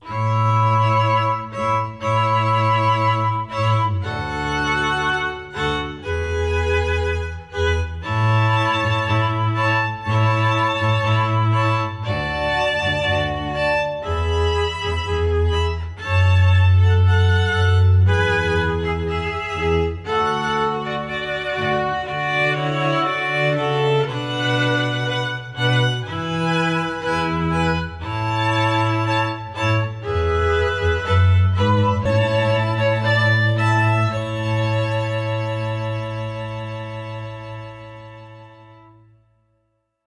(cello, viola, violin, violin)
Here are the string quartets:
The even string quartet